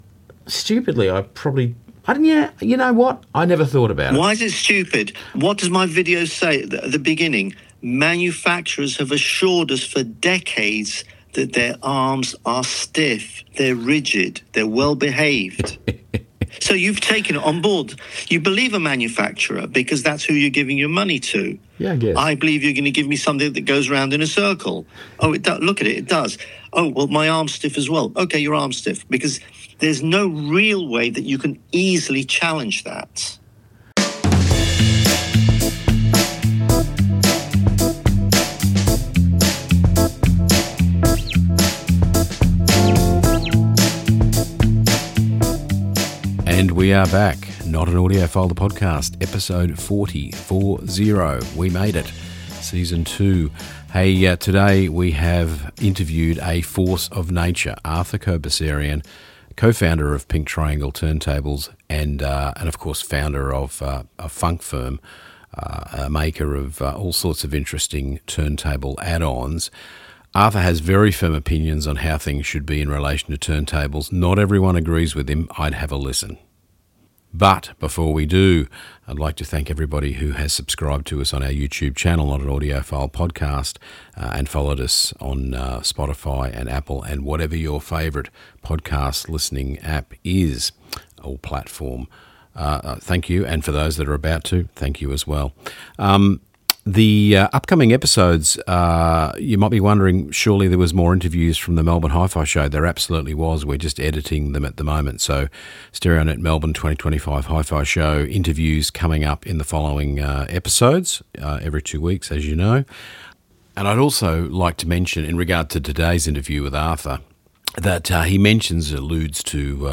Explore HiFi from behind the scenes featuring interviews with designers, engineers and experts discussing speakers, amps, turntables, cables and more. HiFi pros talk design, get technical, offer opinions, provide news, reviews, and insights.